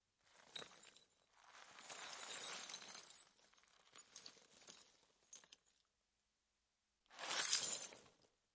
扔掉玻璃
描述：处理并丢弃一些玻璃碎片。在柏林的Funkhaus工作室录制。
Tag: 玻璃 碎片 打破